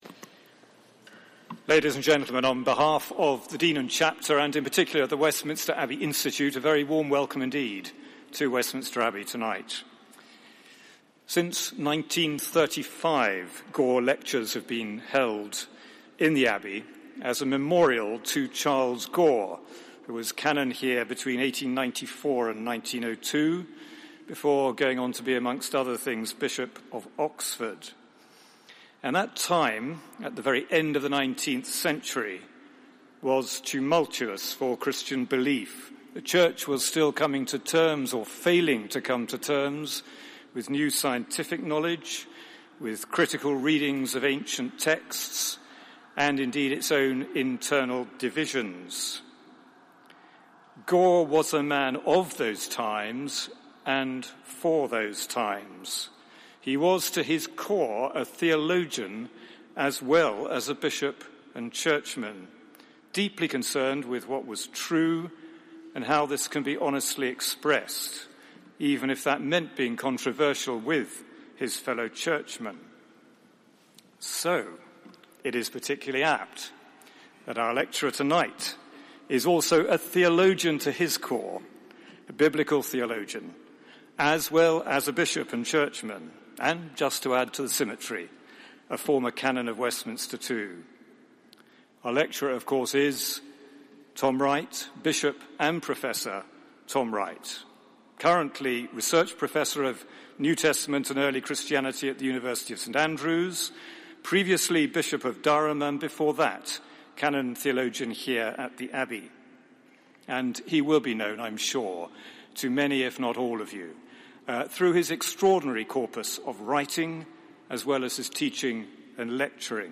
Westminster Abbey / Gore Lecture 2014: The Faithfulness of God